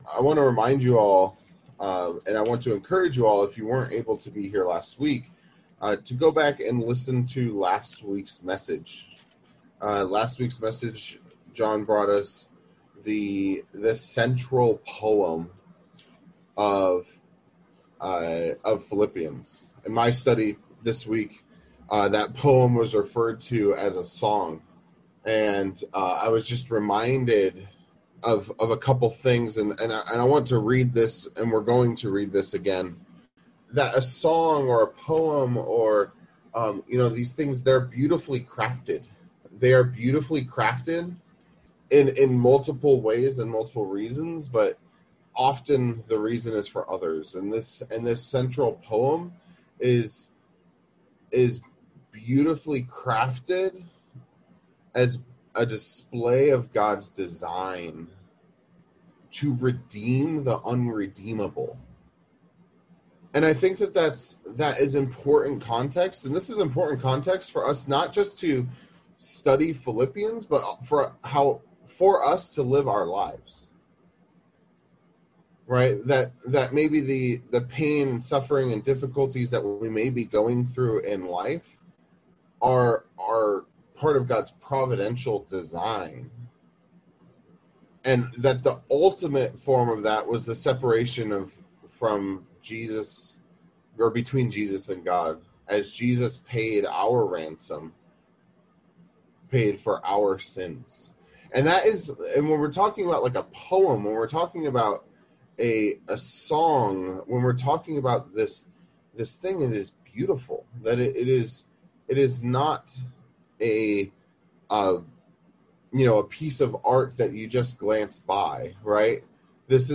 Sermons | La Mesa Village Church